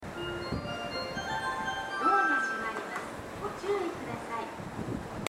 スピーカーはＴＯＡ型が設置されており音質も高音質です。スピーカーの高さも低めですが 音量がやや小さめです。
発車メロディーフルコーラスです。